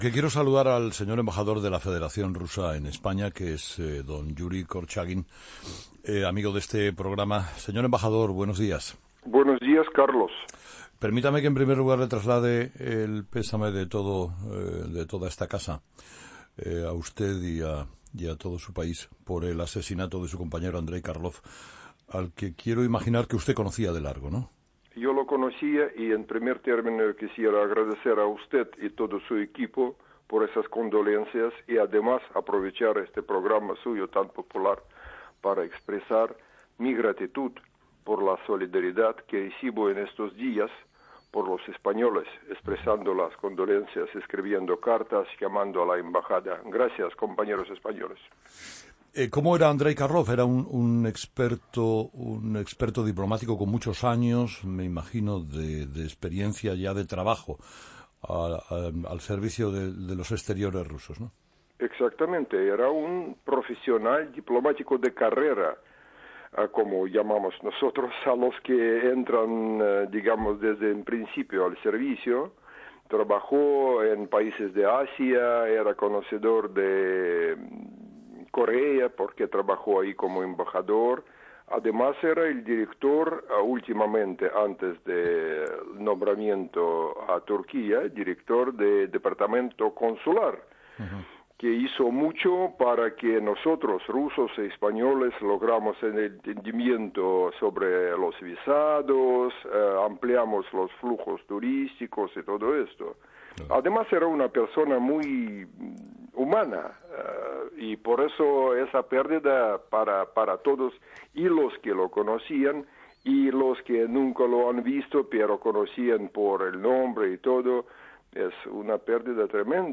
Entrevista a Yuri Korchagin.